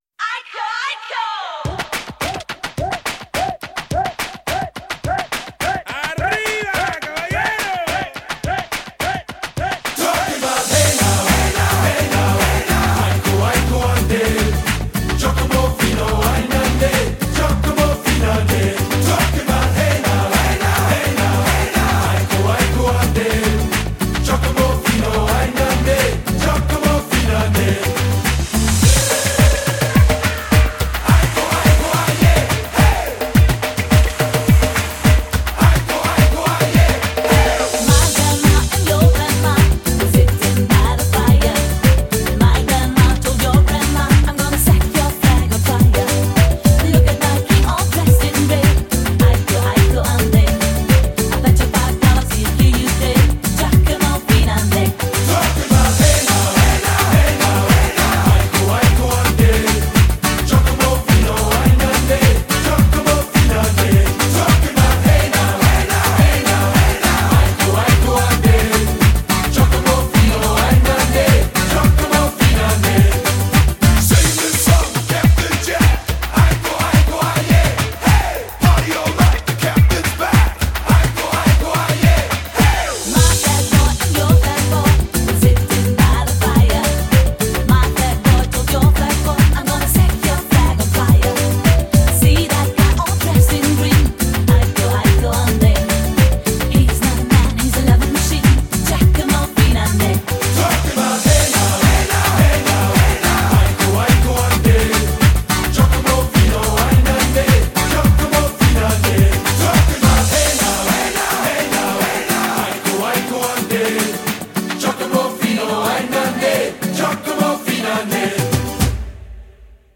BPM106-106